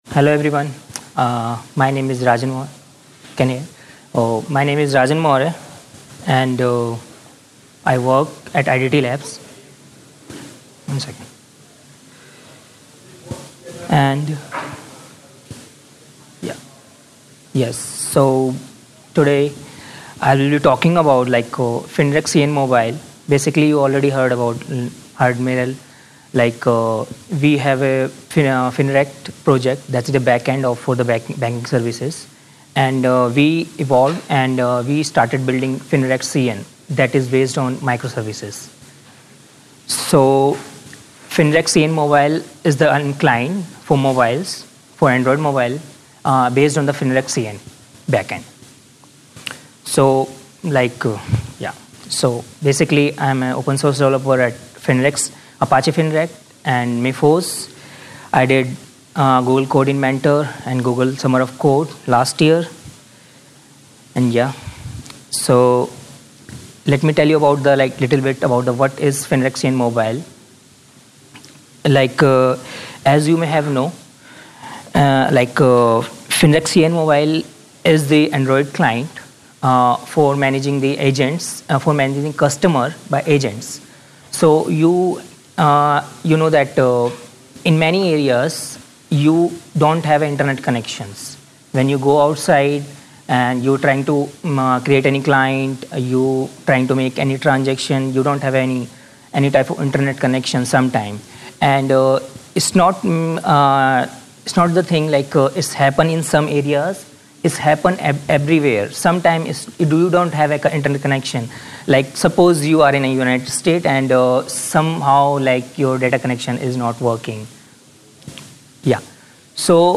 Tags: ApacheCon, apacheconNA2018, Podcasts • Permalink